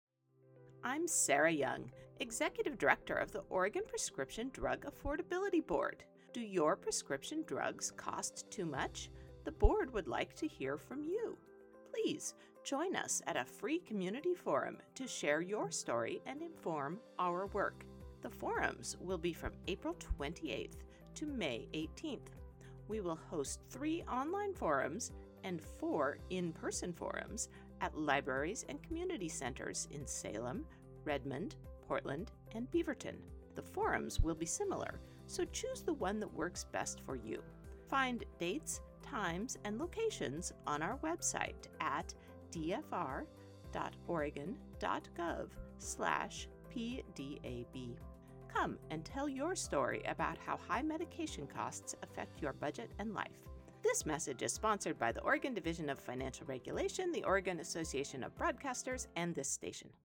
Listen to a PDAB radio ad about the community forums (English)